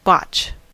botch: Wikimedia Commons US English Pronunciations
En-us-botch.WAV